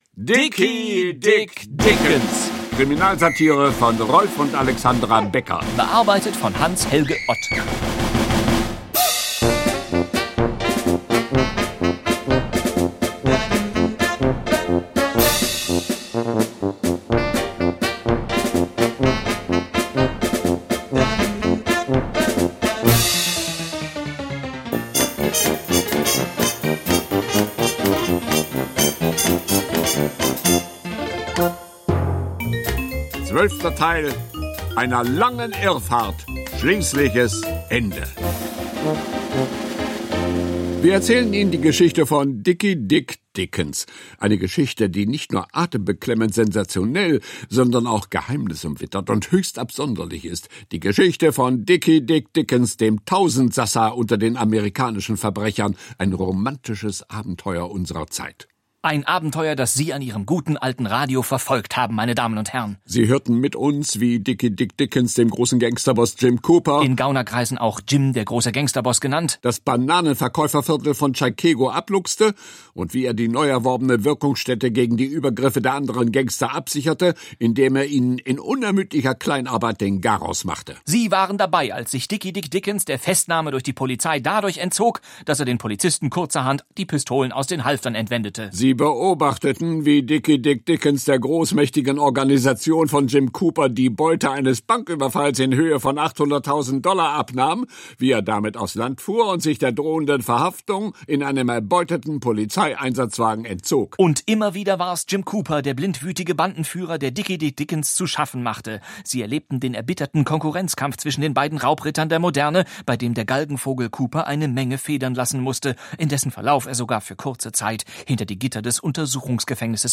Dickie Dick Dickens – Kriminal-Hörspiel-Serie